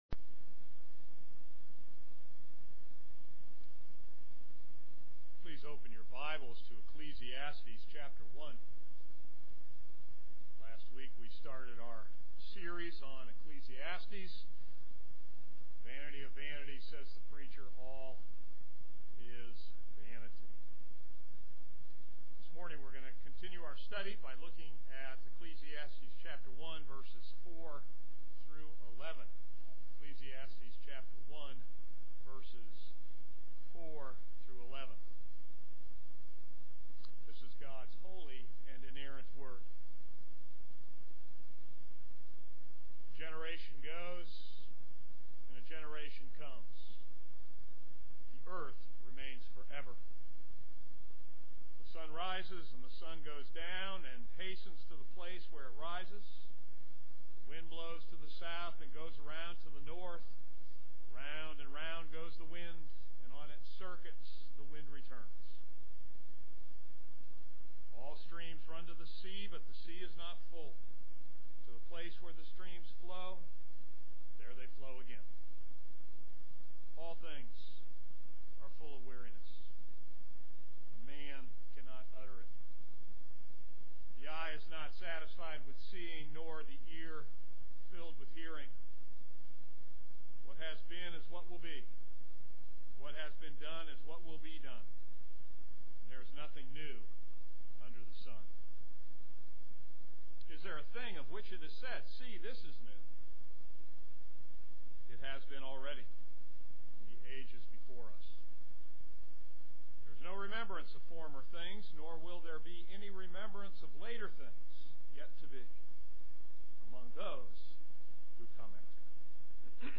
This is a sermon on Ecclesiastes 1:4-11.